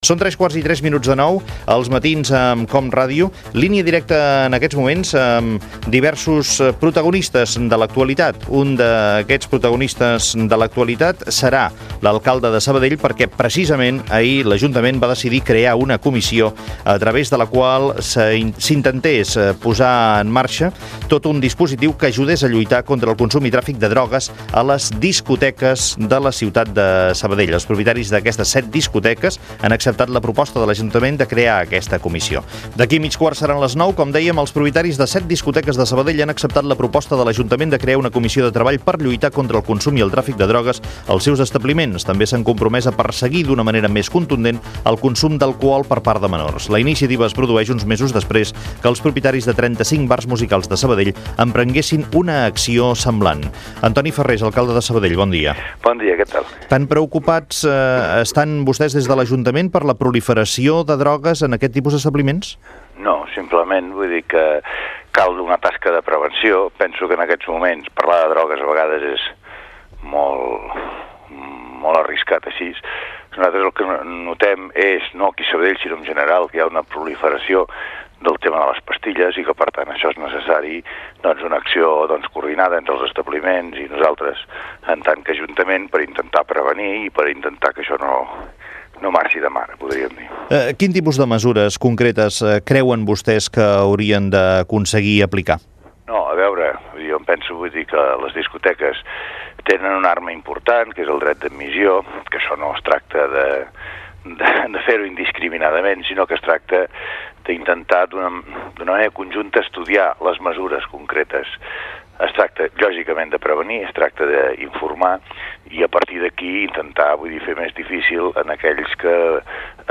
Identificació del programa i fragment d'una entrevista amb l'alcalde de Sabadell, Antoni Farrés, sobre la proliferació de drogues a bars i establiments nocturns.
Info-entreteniment